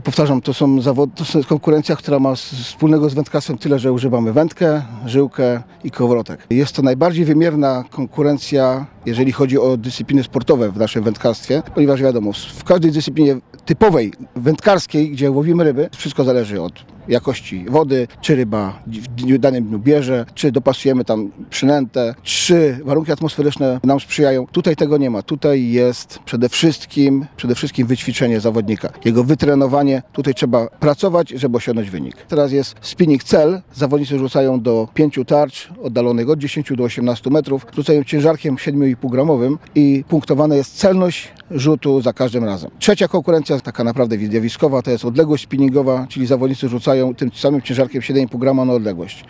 mówił w rozmowie z Radiem 5